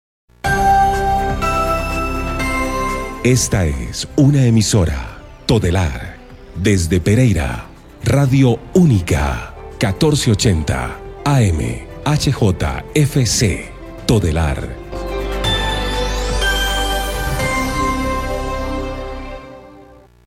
Identificación de Radio Única durante esta década.
IDENTIFICACION-RADIO-ÚNICA-HJFC-1480-AM.mp3